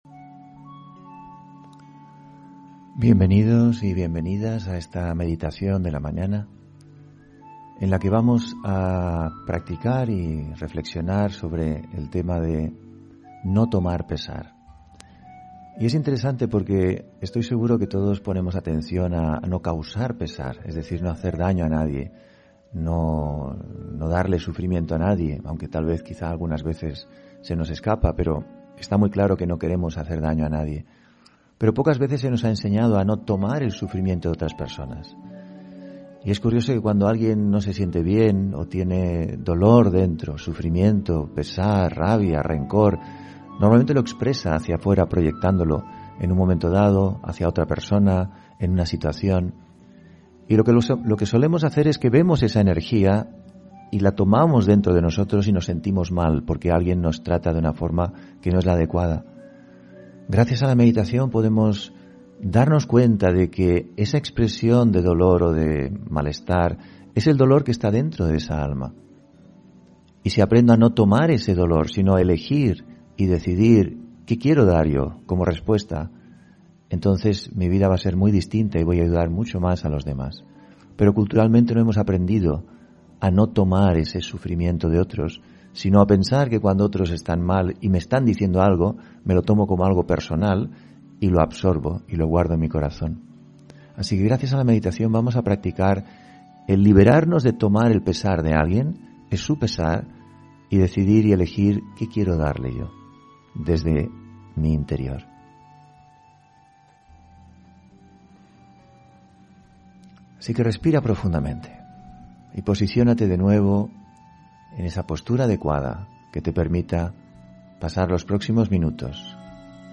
Meditación de la mañana: No tomar pesar